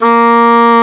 Index of /90_sSampleCDs/Roland LCDP04 Orchestral Winds/WND_English Horn/WND_Eng Horn Sh
WND G#3   01.wav